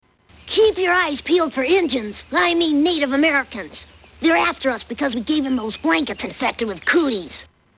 Tags: Media Cooties Cooties Commercials Public Service Announcement Cooties Awareness